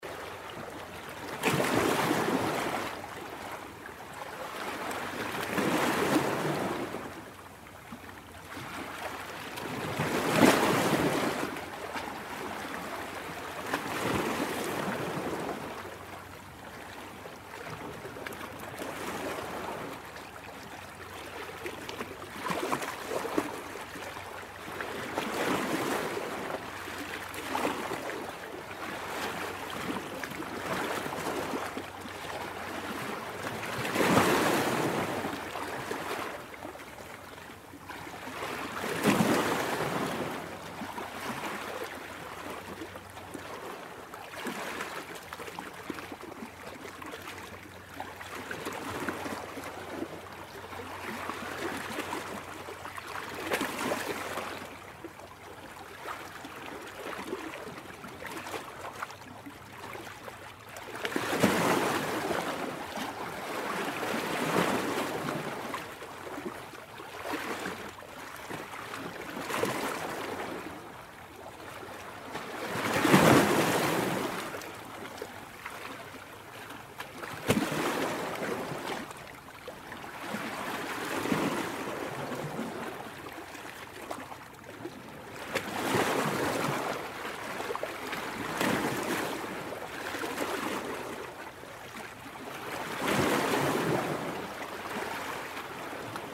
Nature Sounds
Vagues de l’Océan
05.-Waves.mp3